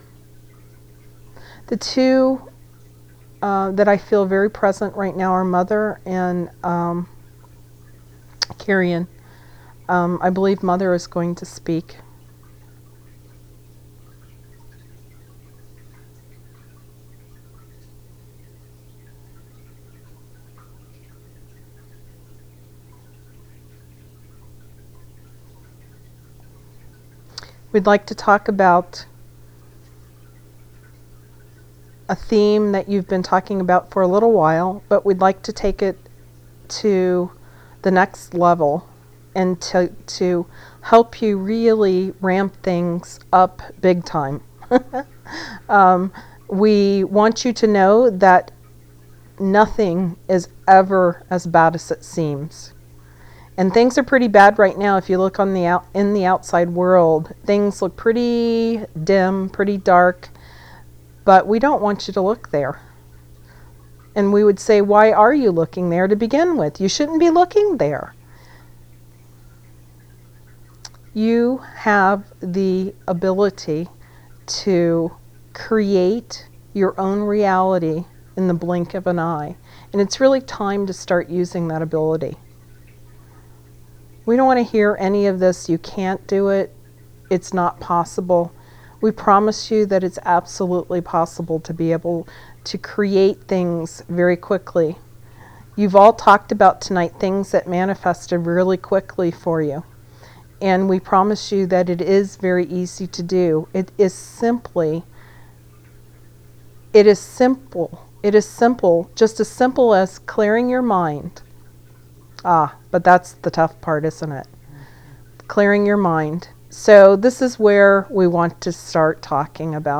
Listen to the live channeled recording.